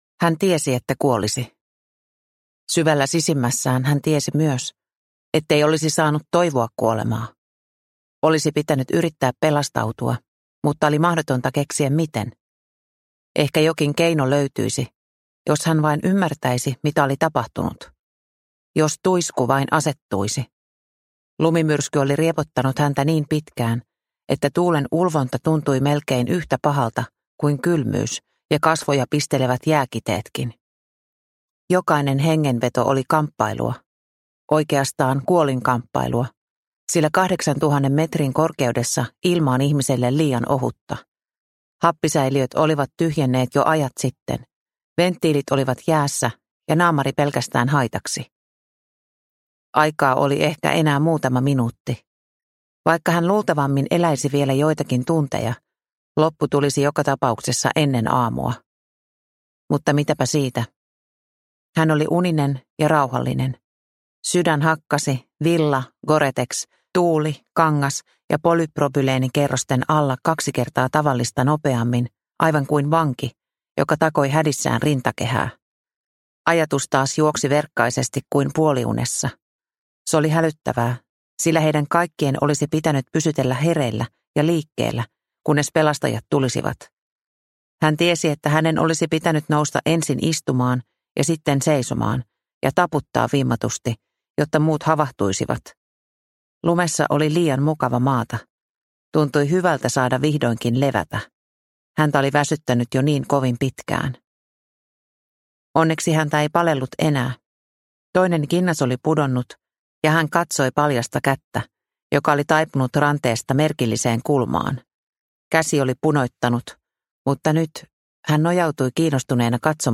Pimeyden syli – Ljudbok – Laddas ner